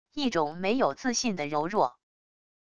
一种没有自信的柔弱wav音频